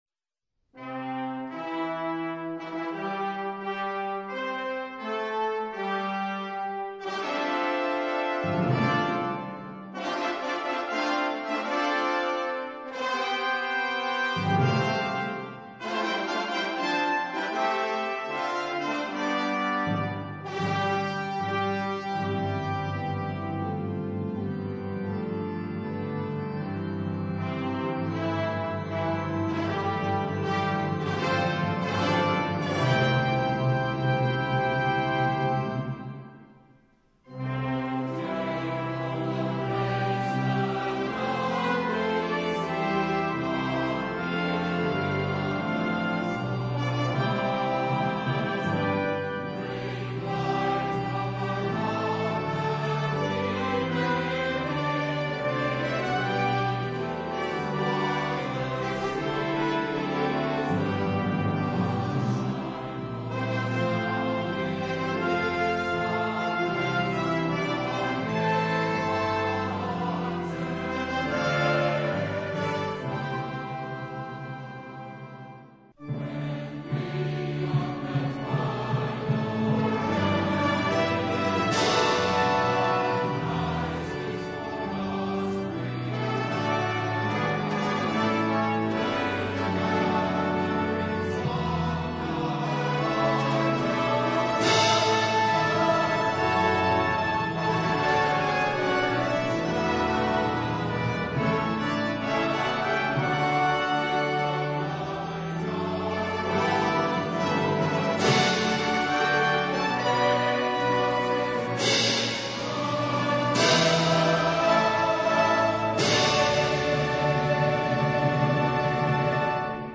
Voicing: Congregation